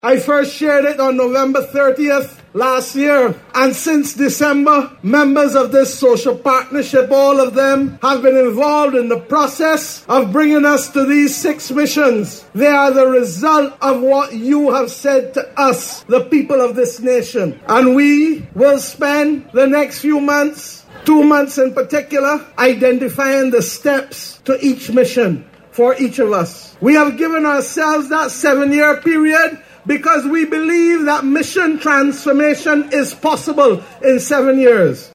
The social partnership yesterday, signed the declaration of Mission Barbados document during Barbados Worker’s Union’s May Day Celebrations at the National Botanical Gardens.
Speaking at the signing Prime Minister Mia Amor Mottley explained that they will be assessing the best way to approach each mission over the next two months.